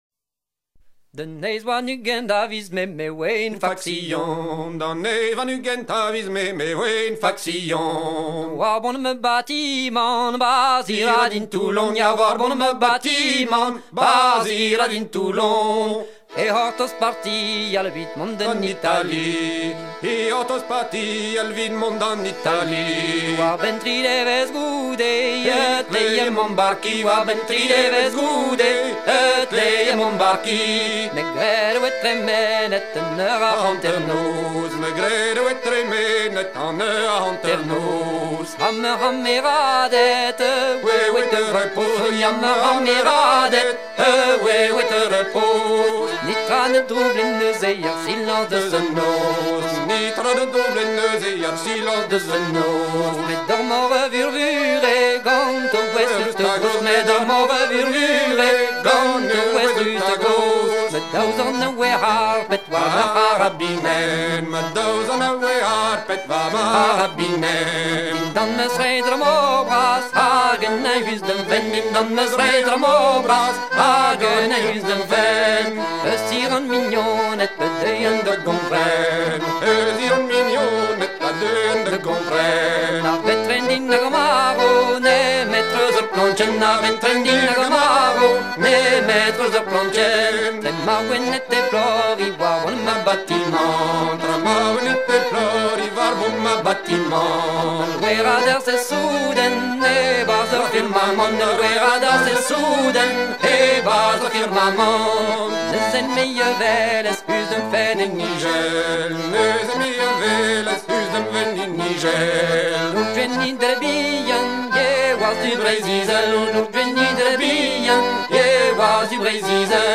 mélodie d'air à la marche du Centre Bretagne
Pièce musicale éditée